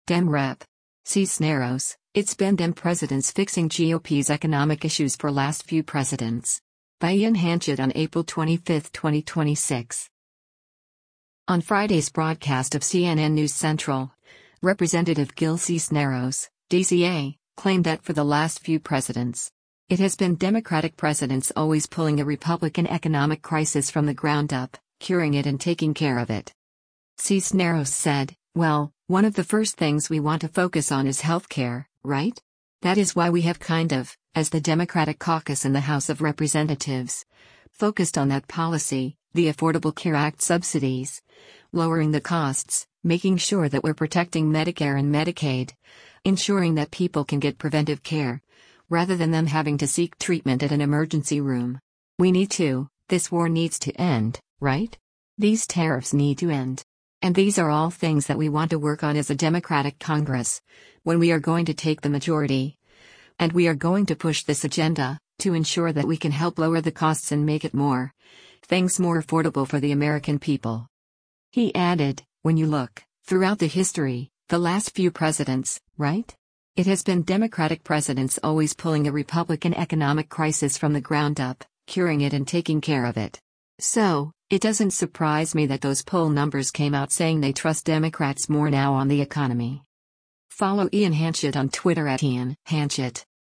On Friday’s broadcast of “CNN News Central,” Rep. Gil Cisneros (D-CA) claimed that for “the last few presidents,” “It has been Democratic presidents always pulling a Republican economic crisis from the ground up, curing it and taking care of it.”